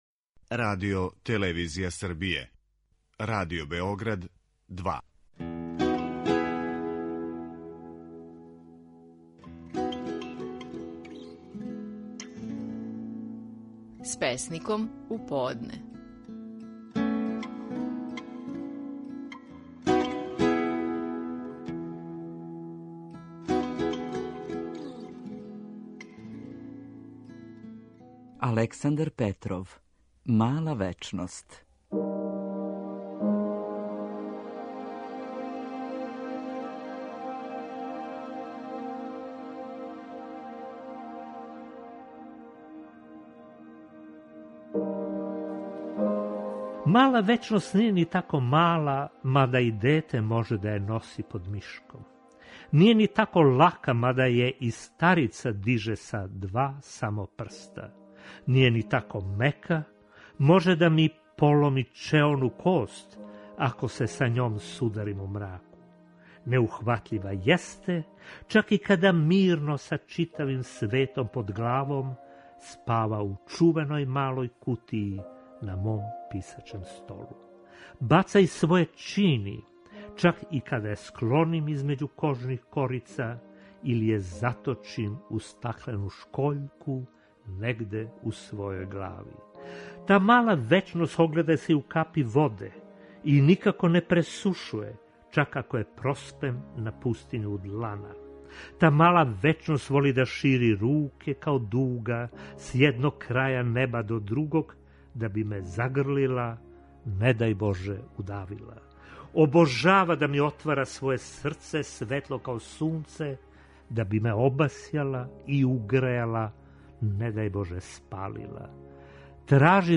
Стихови наших најпознатијих песника, у интерпретацији аутора.
У данашњој емисији, своју песму „Мала вечност" говори песник Александар Петров.